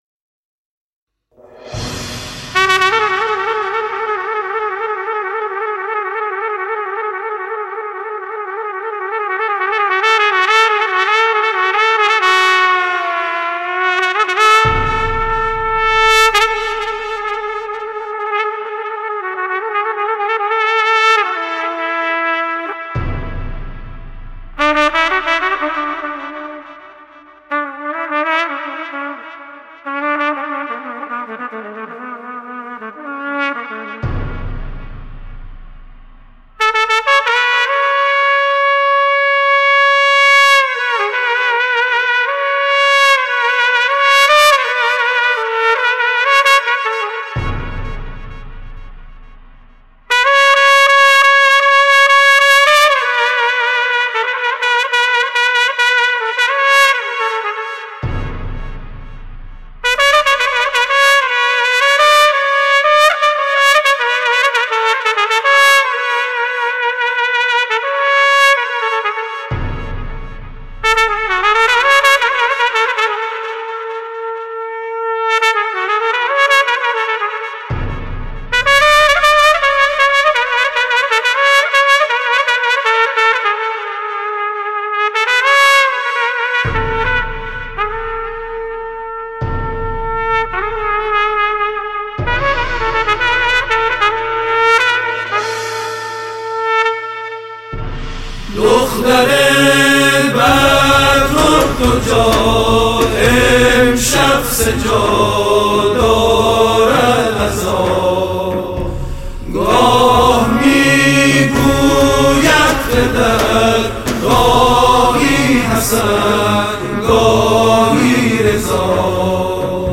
کارهای استودیویی